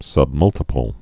(sŭb-mŭltə-pəl)